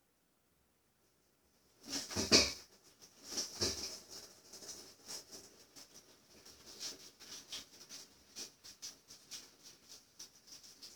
Bruit de choc dans colonne écoulement des toilettes logement neuf
Nous habitons au 1er sur 6 étage et dès que les chasses d'eau avec leur contenu sont tirées dans les étages, nous entendons un fort bruit de choc à notre niveau.
Suite voici la photo, je rajoute également le son que j'ai enregistré dans nos toilettes.
son ecoulement toilettes 2 du 02 10 13.mp3
bruit colonne écoulement WC